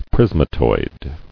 [pris·ma·toid]